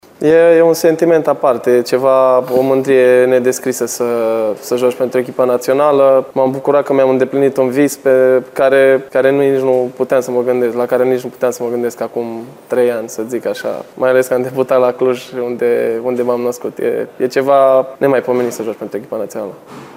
Portarul echipei naţionale de fotbal a României, Horaţiu Moldovan, a declarat, într-un interviu acordat FRF TV înaintea partidelor cu Israel şi Kosovo, că prima reprezentativă are o şansă foarte mare să se califice la turneul final al Campionatului European din 2024.